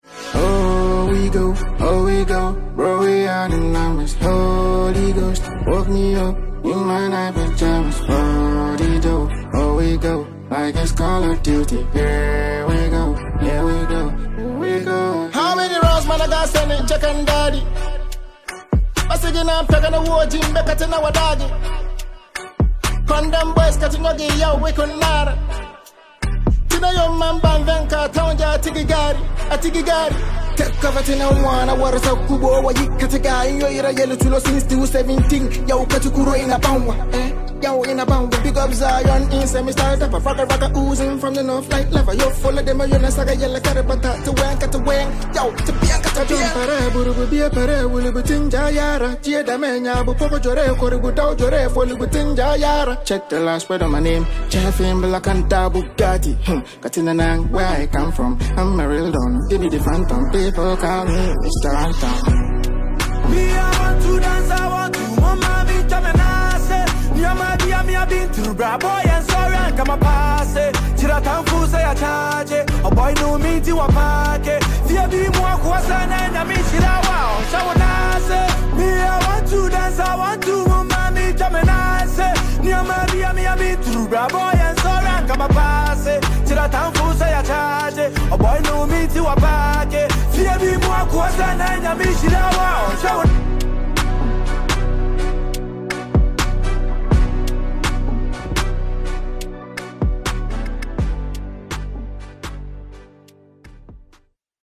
Genre: Highlife / Afrobeat